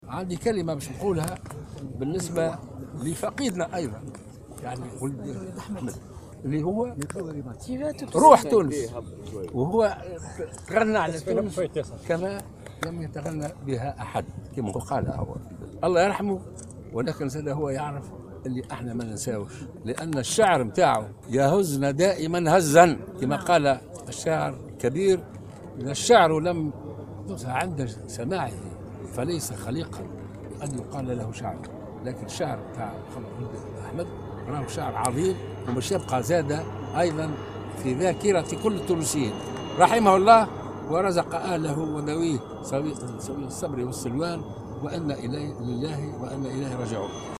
رثى رئيس الجمهورية الباجي قائد السبسي خلال حضوره اليوم الأربعاء 6 أفريل 2016 في حفل تدشين تمثال للزعيم الحبيب بورقيبة أمام القصر الرئاسي بالمنستير بمناسبة الذكرى 16 لرحيله فقيد الساحة الثقافية الشاعر الصغير أولاد حمد.